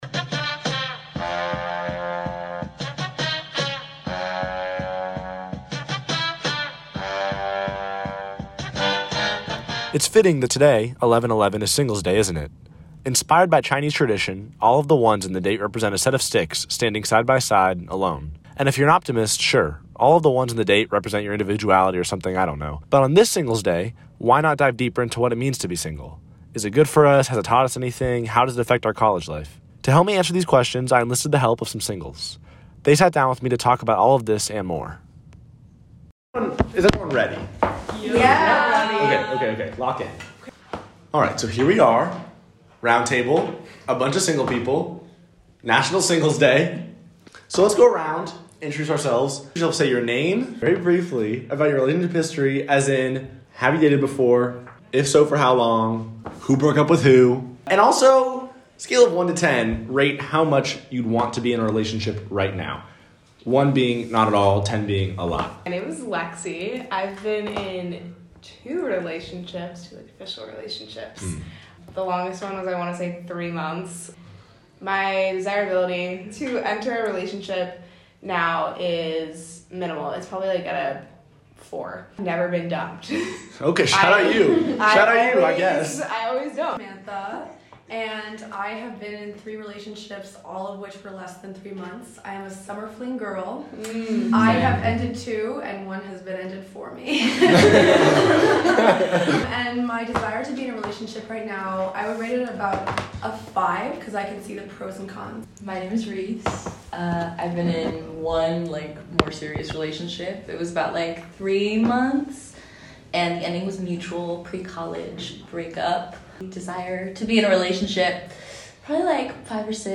This episode originally aired as part of our 11/11 Special Broadcast.